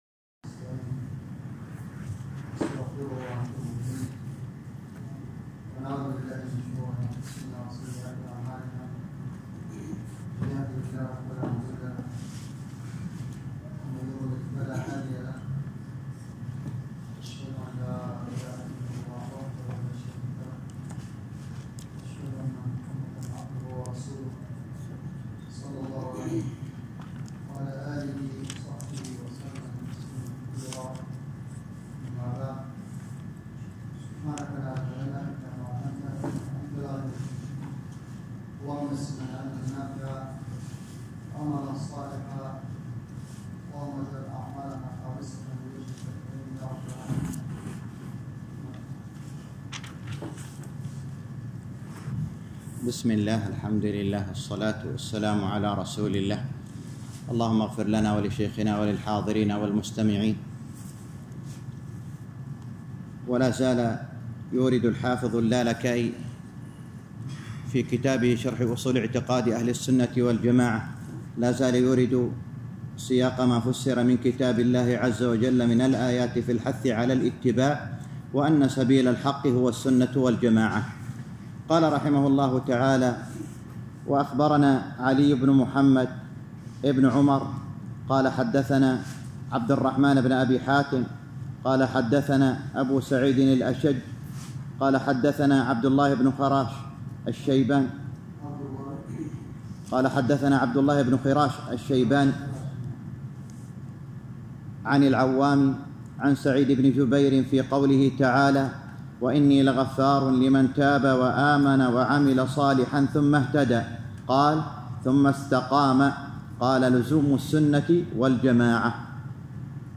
الدرس السادس عشر - شرح أصول اعتقاد اهل السنة والجماعة الامام الحافظ اللالكائي _ 16